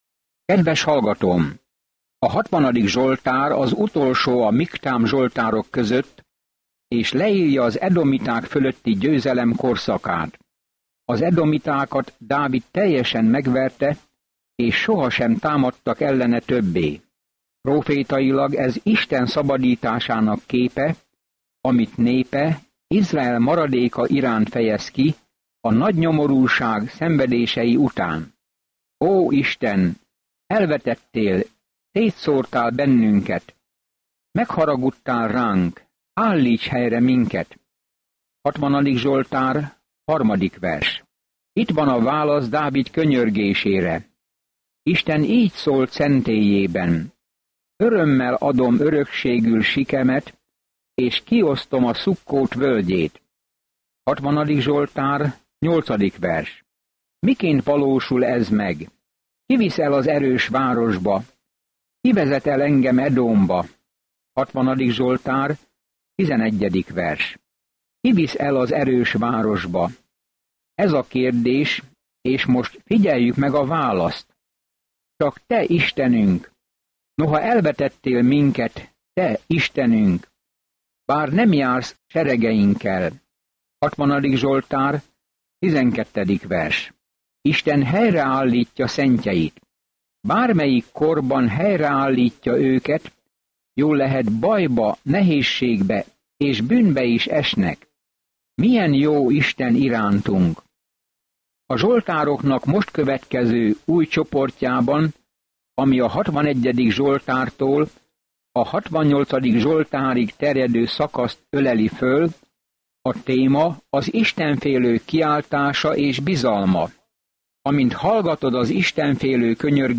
Szentírás Zsoltárok 60 Zsoltárok 61 Zsoltárok 62:1-6 Nap 33 Olvasóterv elkezdése Nap 35 A tervről A zsoltárok gondolatait és érzéseit adják nekünk az Istennel kapcsolatos élmények sorában; Valószínűleg mindegyik eredetileg megzenésített. Napi utazás az Zsoltárok keresztül, miközben hallgatod a hangos tanulmányt, és olvasol válogatott verseket Isten szavából.